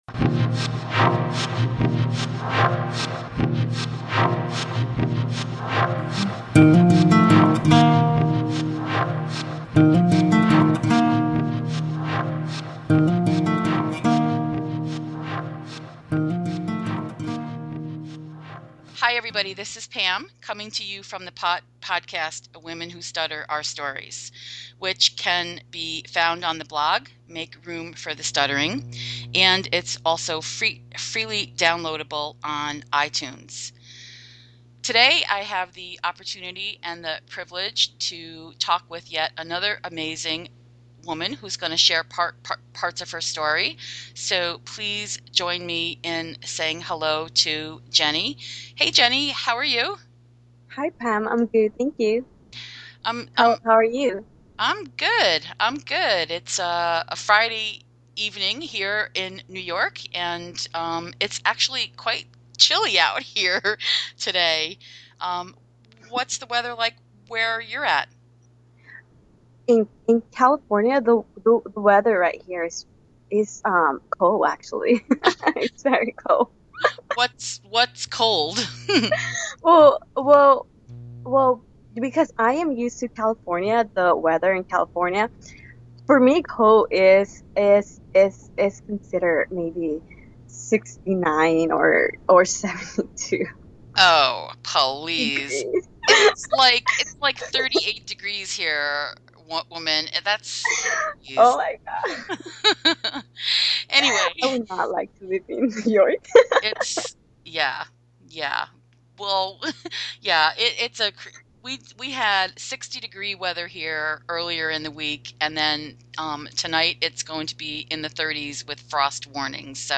We both got choked up at the end of our chat, as we realized we had made a real emotional connection.